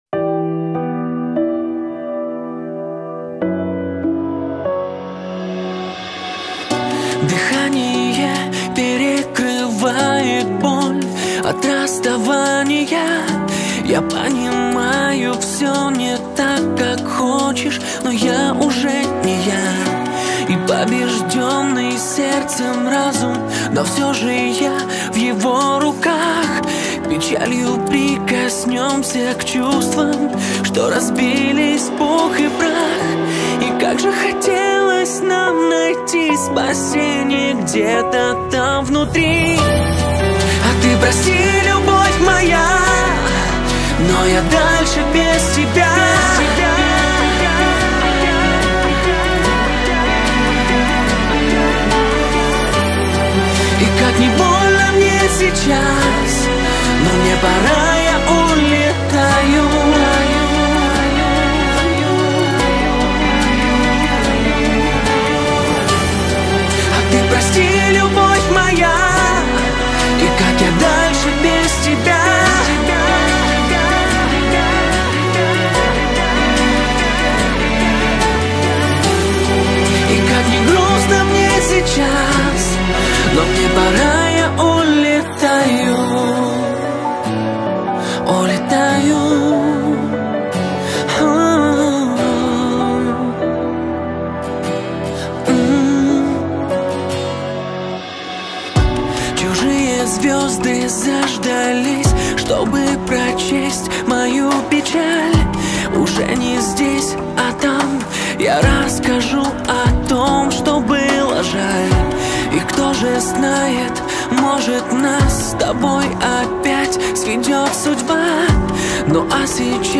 Скачать музыку / Музон / Музыка Шансон